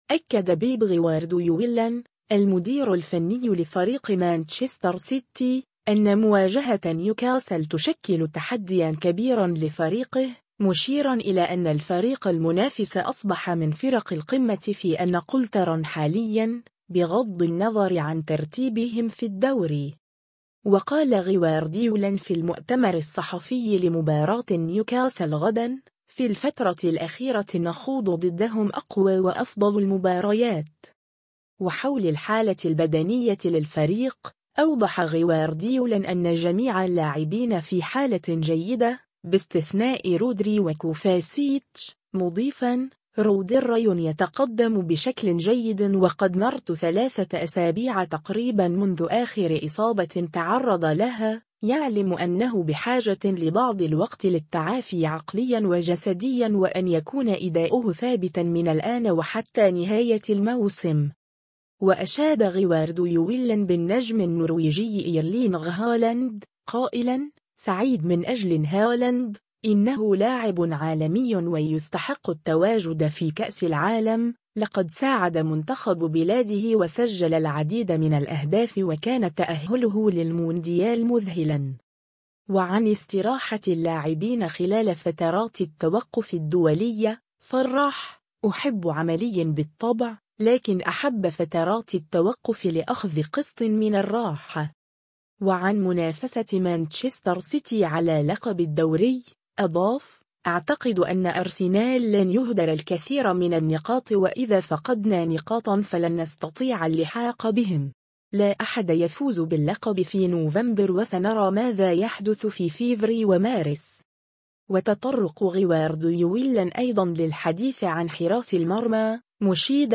و قال غوارديولا في المؤتمر الصحفي لمباراة نيوكاسل غدا : “في الفترة الأخيرة نخوض ضدهم أقوى و أفضل المباريات”.